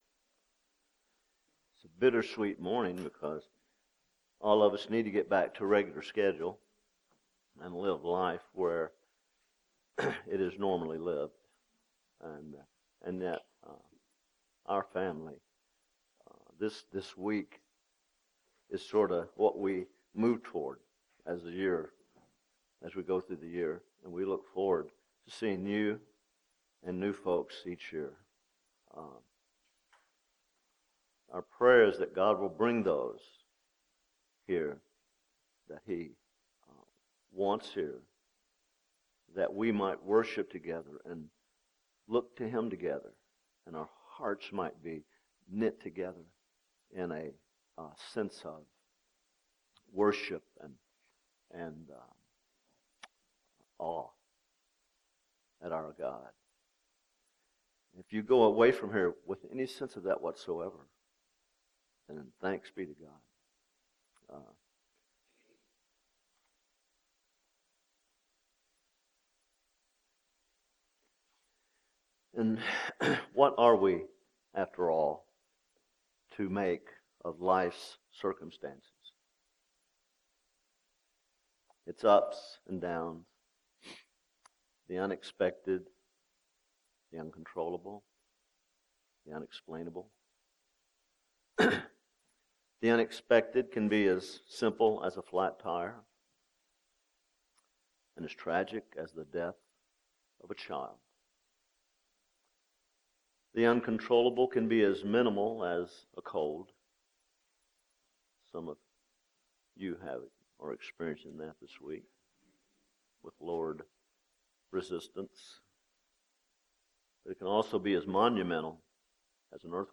P4C14 audios are now available for you to download and continue to glean and grow from the incredible truths we heard at Passion4Christ Summit 2014.